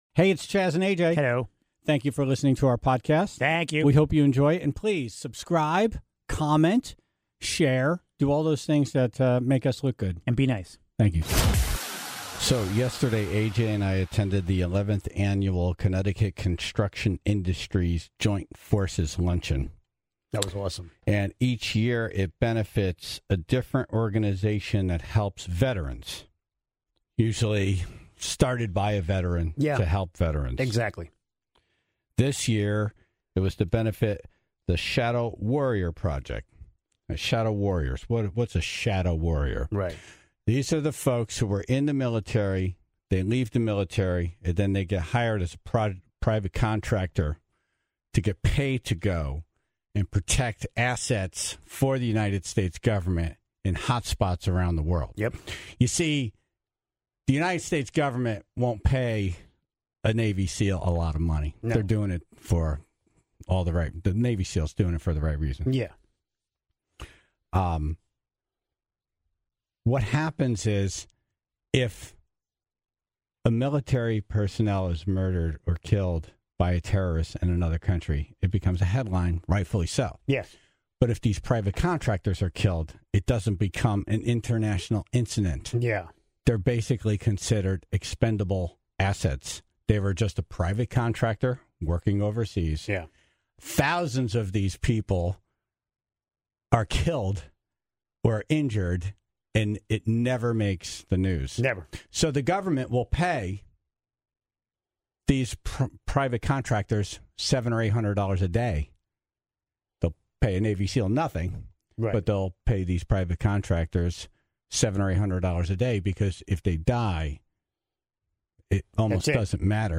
A Tribe member called to share his stories, having been hit TWICE.